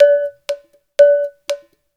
120 -UDU 0FL.wav